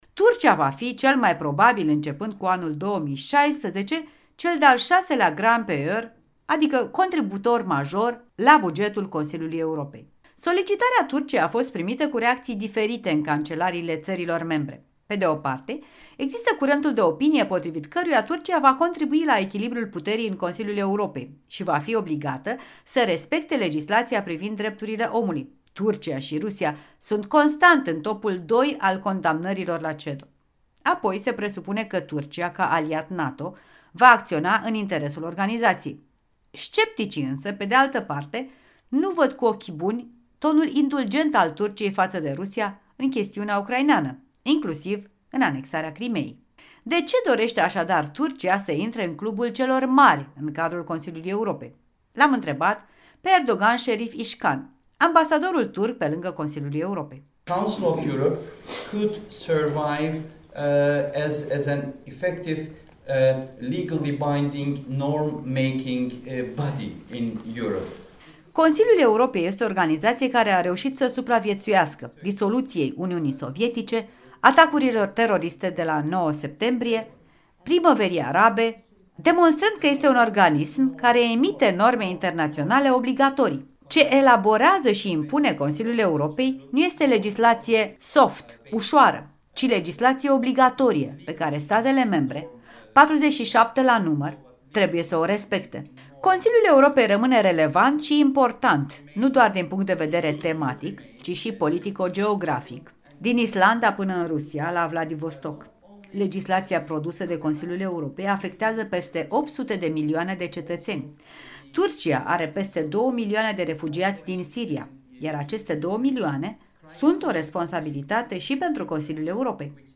În direct de la Strasbourg cu Erdoğan Şerif Ișcan, ambasadorul turc de pe lângă CoE